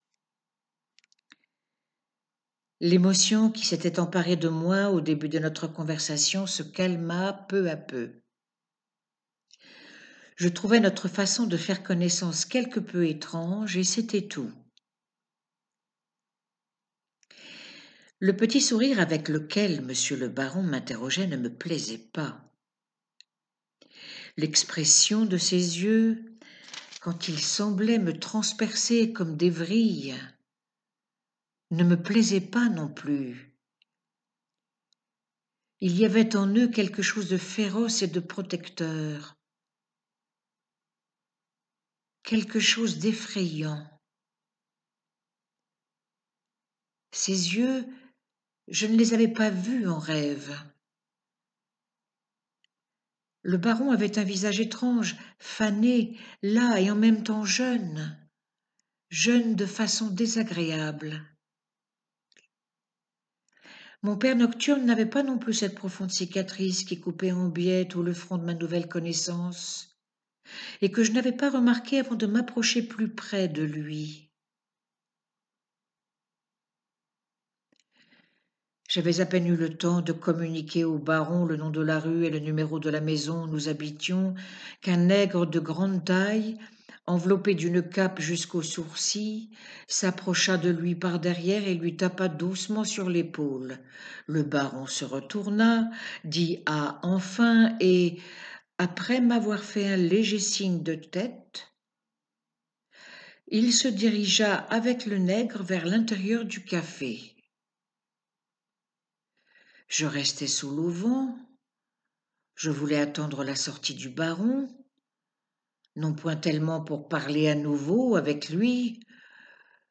Un rêve de Tourguéniev - Récit lu